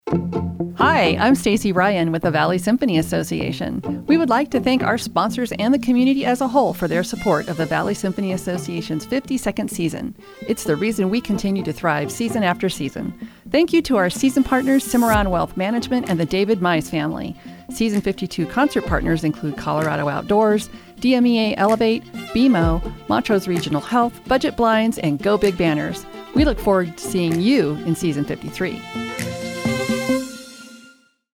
Radio Ad: Thank you for a great 52nd season